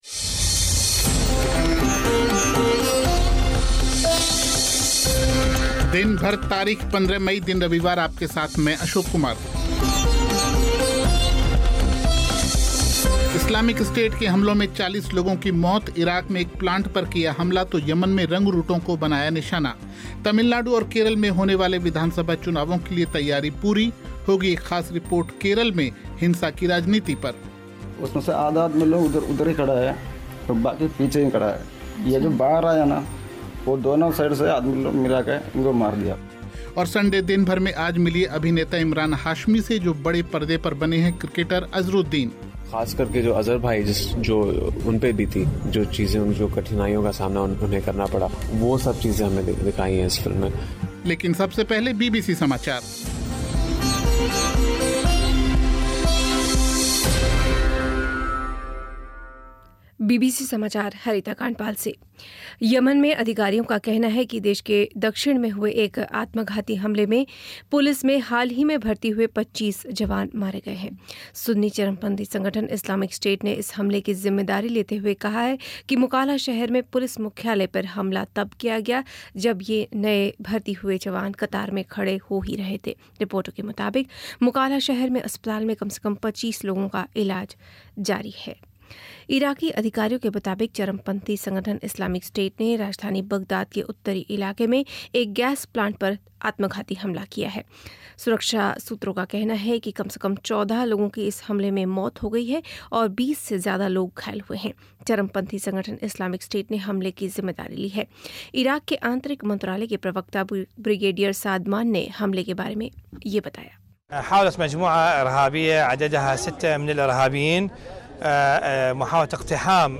इस्लामिक स्टेट के हमलों में 40 लोगों की मौत, इराक में गैस प्लांट पर किया हमला, तो यमन में रंगरूटों को बनाया निशाना. तमिलनाडु और केरल में मतदान की तैयारियां पूरी. सुनिए एक खास रिपोर्ट में केरल में हिंसा की राजनीति पर. और संडे दिन भर में आज मिलिए अभिनेता इमरान हाशमी से. होंगी खबरें खेल की भी.